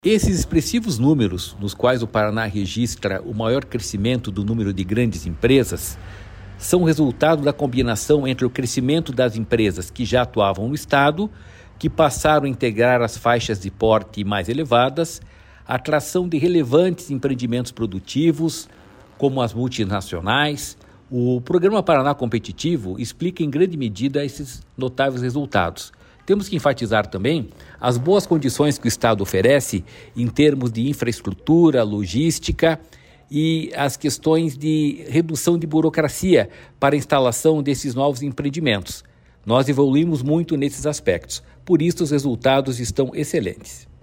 Sonora do presidente do Ipardes, Jorge Callado, sobre o Paraná ter registrado expansão no número de grandes empresas acima da média nacional